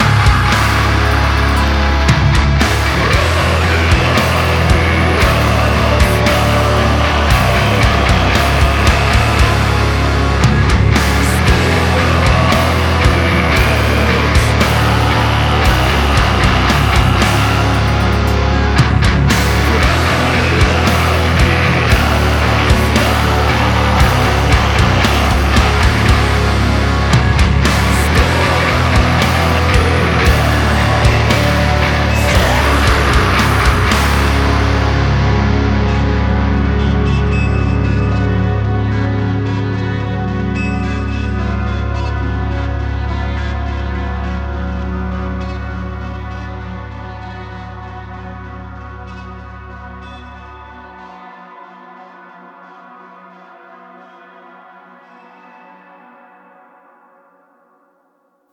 не понравилось как вокалист скримил
но пока так для черновика вокал висит